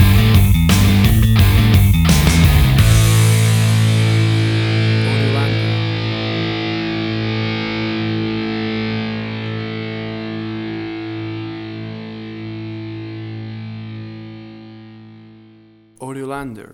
WAV Sample Rate: 16-Bit stereo, 44.1 kHz
Tempo (BPM): 86